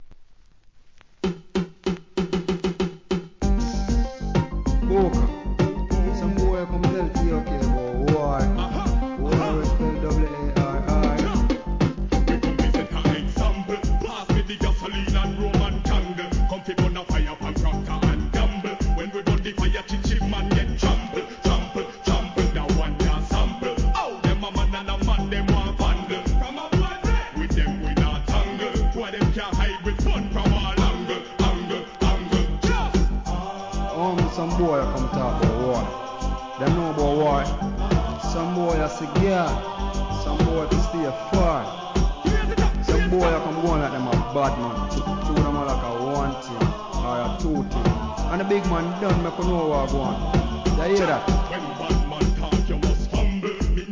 REGGAE
「ア〜アア〜ア〜♪」のコーラスでお馴染みのチュ〜ン!!